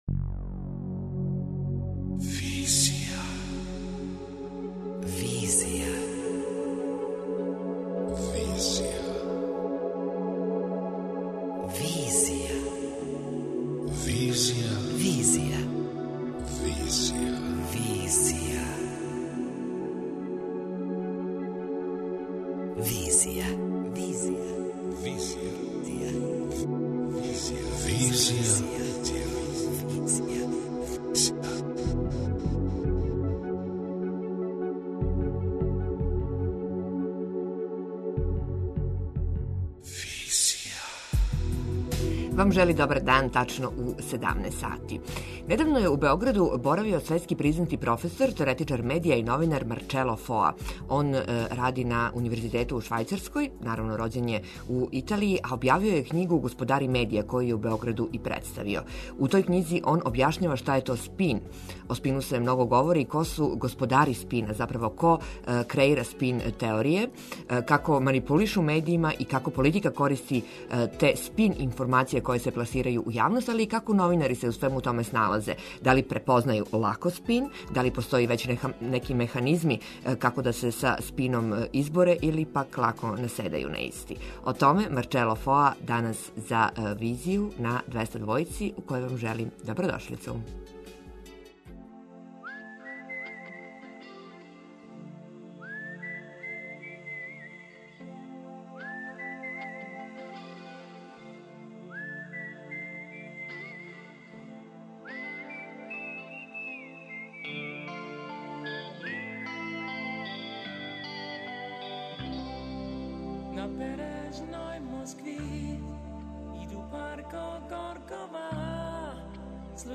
преузми : 27.08 MB Визија Autor: Београд 202 Социо-културолошки магазин, који прати савремене друштвене феномене.